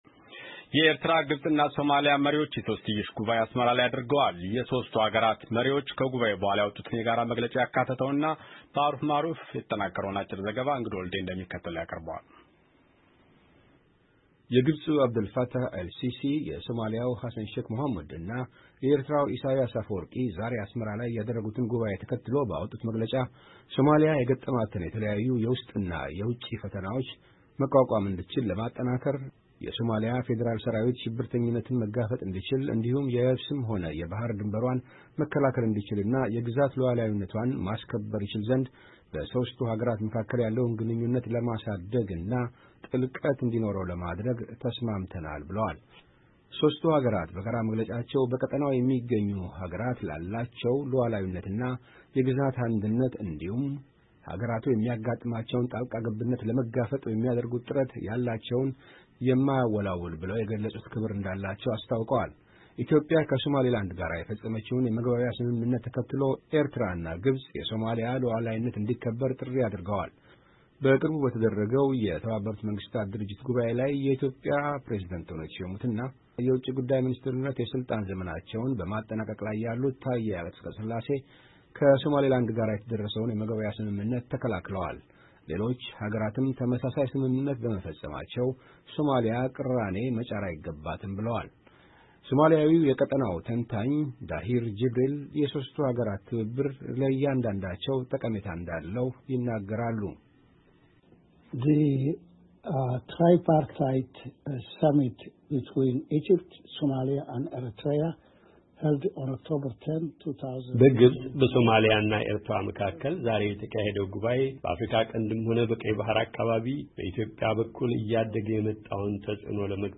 አጭር ዘገባ